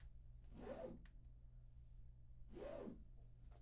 描述：卷帘的快门向上或向下移动
Tag: 幻灯片 窗口 快门